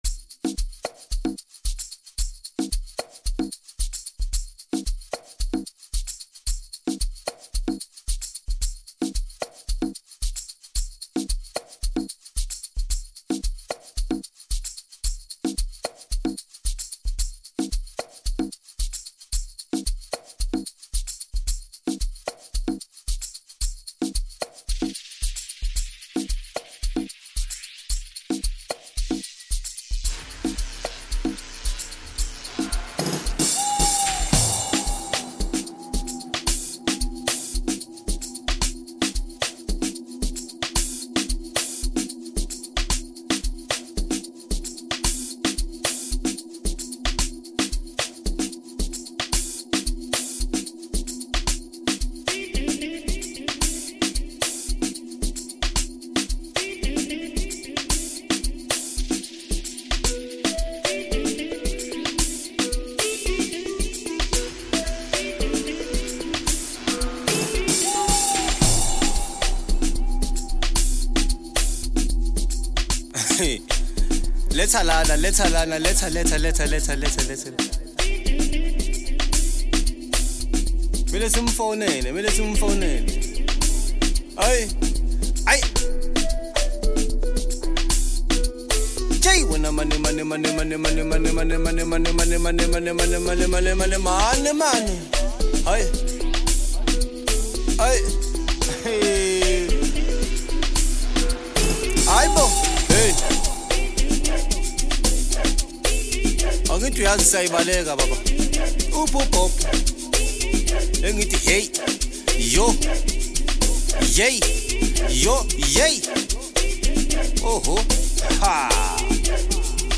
Home » Amapiano » Deep House » Hip Hop » Latest Mix